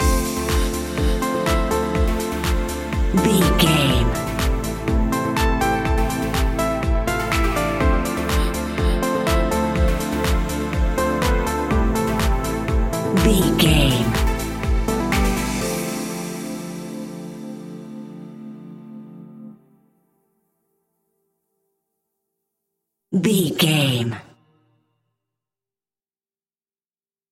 Aeolian/Minor
groovy
smooth
uplifting
drum machine
synthesiser
electric piano
electro house
synth bass